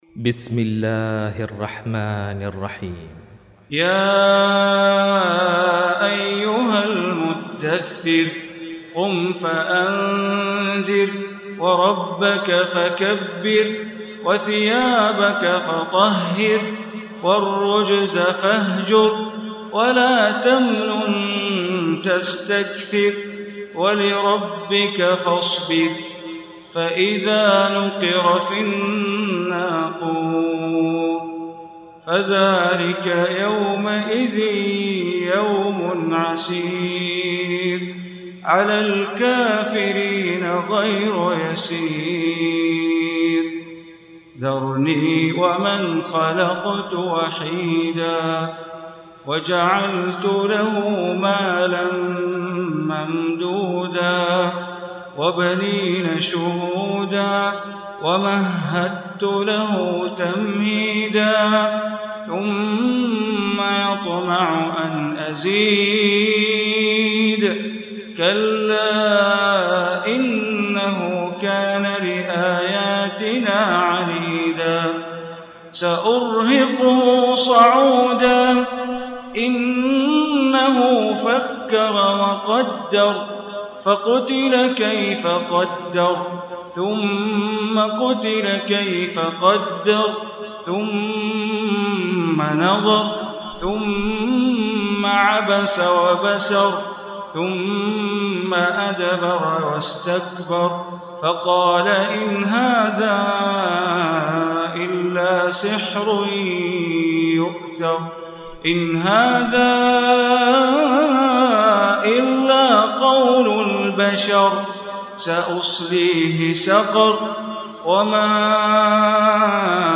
Surah Mudassir Recitation by Sheikh Bandar Baleela
74-surah-mudasir.mp3